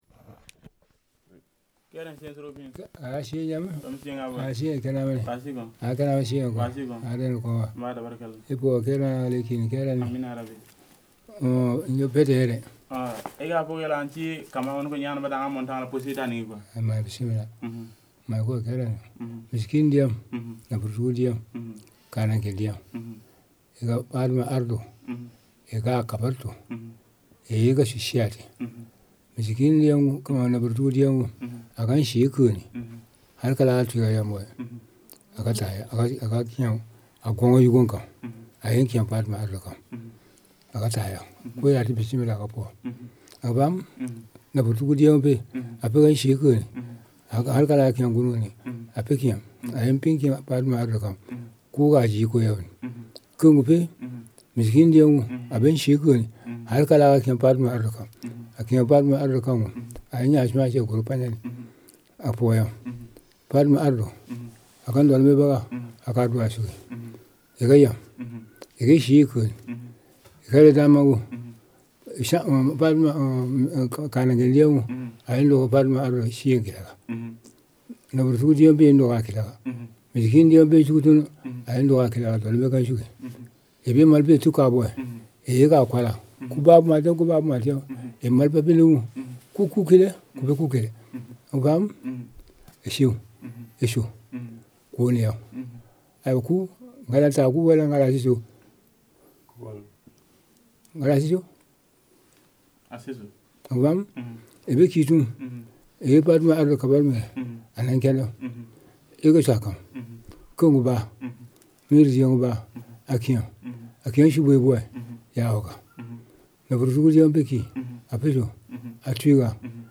• field recordings in mp3 format made with digital microphone in Namagué village, Mali.